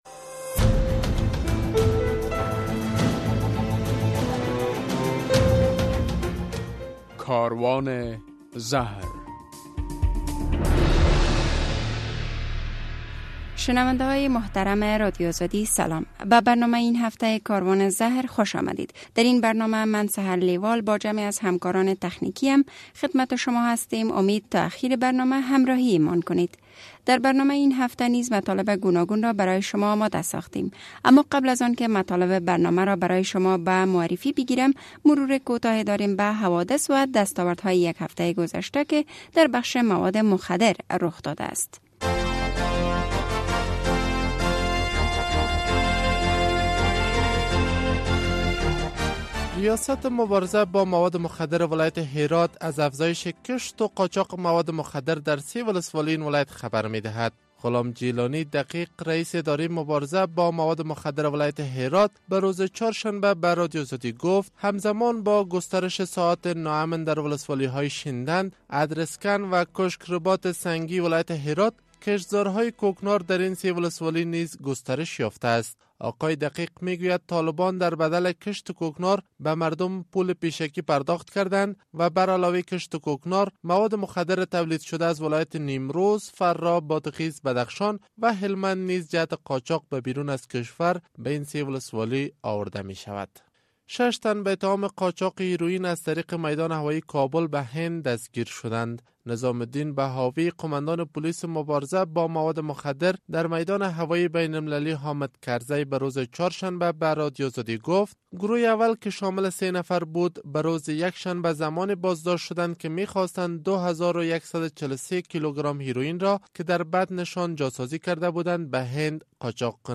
در برنامه این هفته کاروان زهر، در نخست خبرها، بعداً گزارش‌ها، بعد از آن مصاحبه و به تعقیب آن خاطره یک معتاد و ...